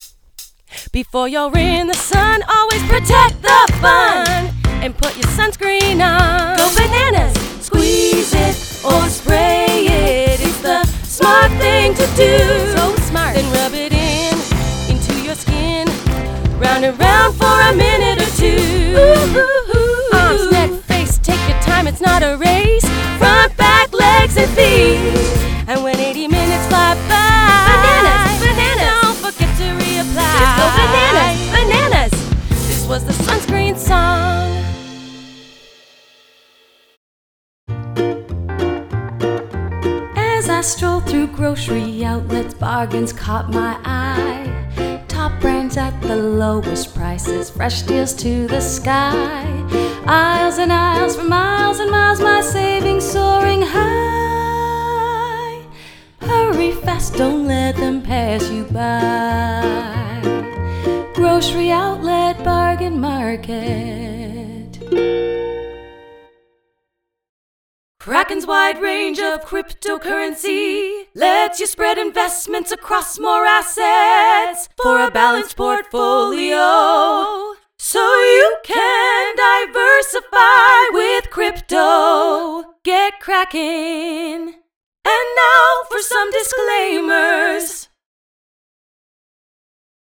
Female
Singing
Jingles. Mezzo Sop. Wide Range